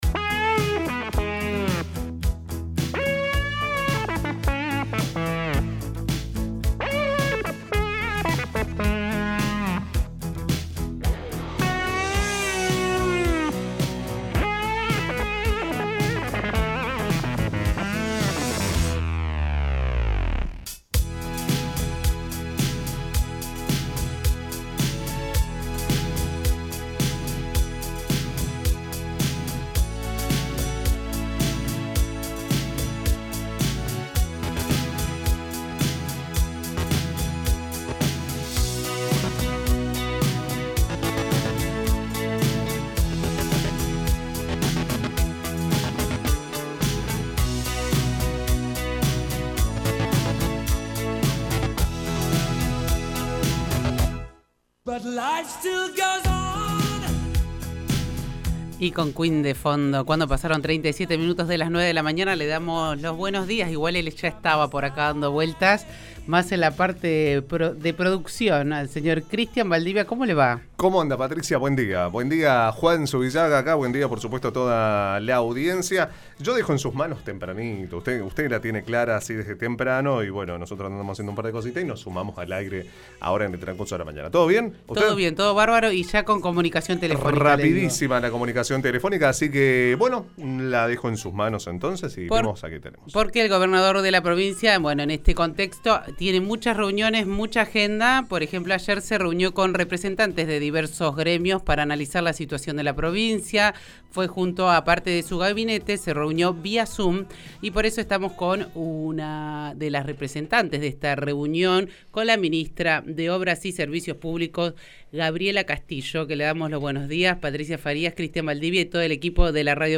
La Ministra de Obras y Servicios Públicos, Gabriela Castillo, en comunicación con la Radio Pública Fueguina- FM103.1 detalló sobre la reunión que mantuvo el Gobierno Provincial con representantes de diversos gremios para analizar la situación de la provincia, asimismo resaltó el trabajo que lleva adelante la gestión en medio de la pandemia.